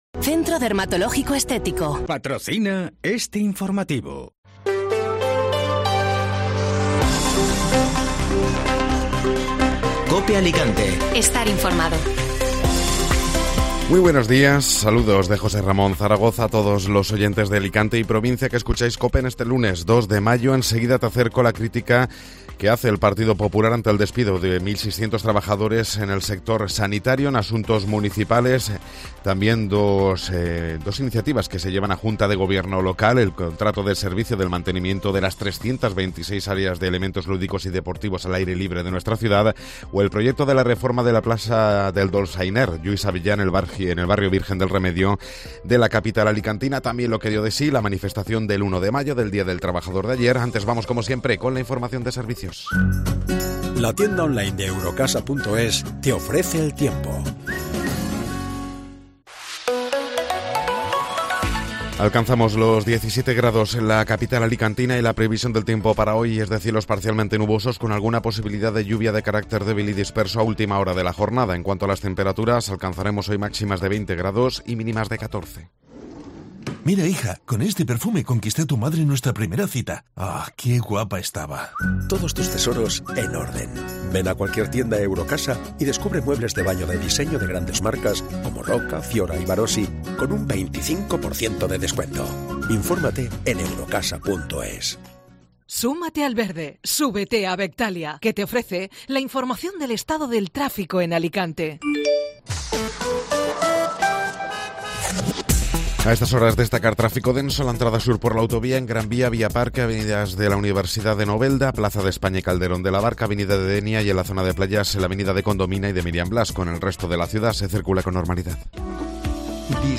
Informativo Matinal (Lunes 2 de Mayo)